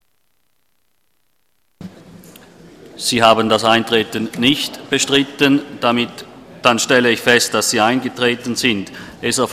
Session des Kantonsrates vom 24. und 25. Februar 2014